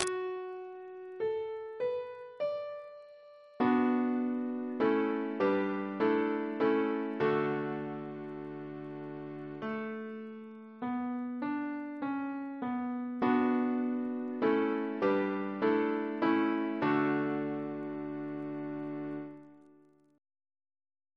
Double chant in D Composer: Sir H. Walford Davies (1869-1941), Organist of the Temple Church and St. George's, Windsor Reference psalters: ACP: 303; CWP: 171; H1982: S188; RSCM: 151